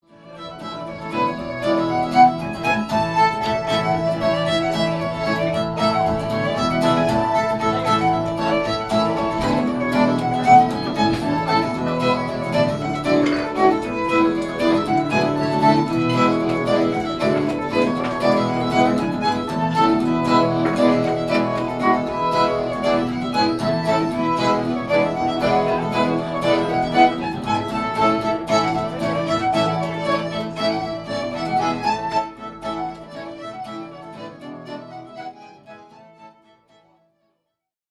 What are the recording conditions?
Live recording debut performance